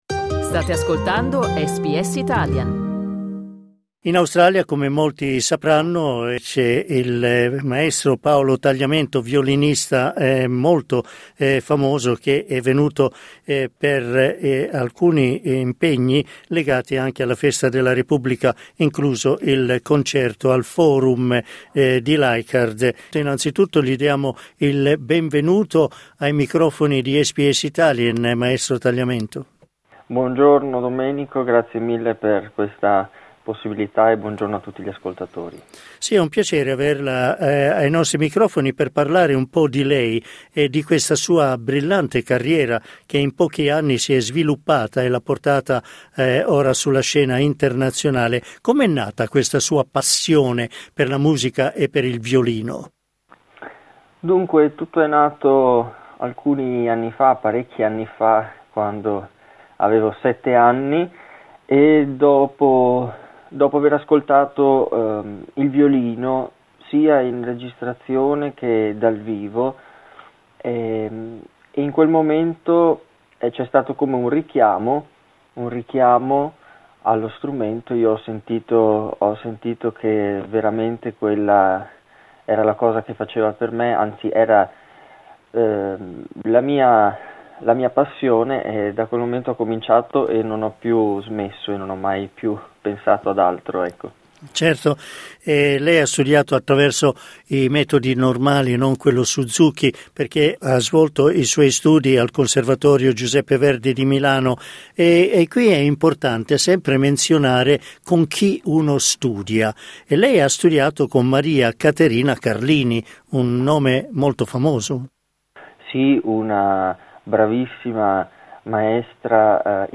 Clicca sul tasto "play" in alto per ascoltare l'intervista Credit: Consolato d'Italia di Sydney.